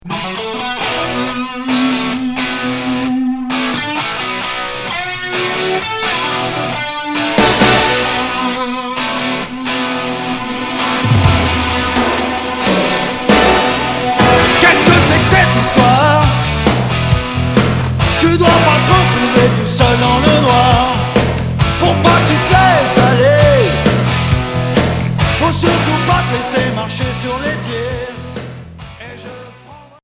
Hard mélodique